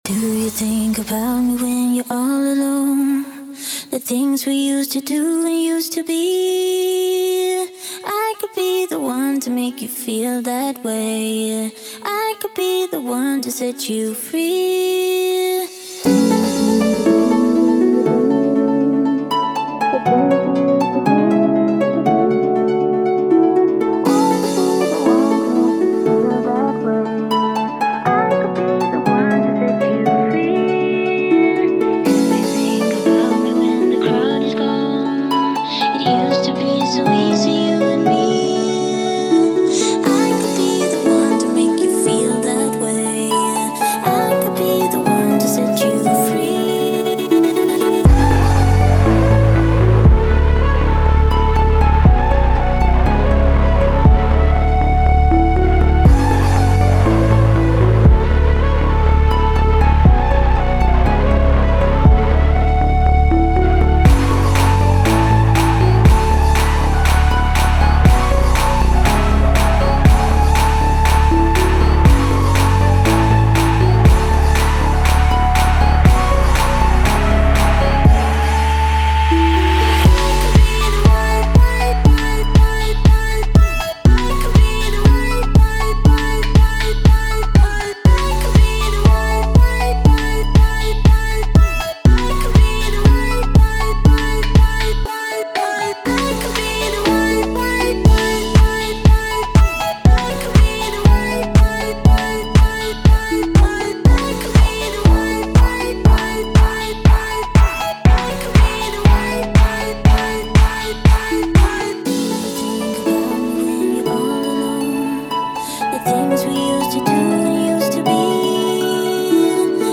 120BPM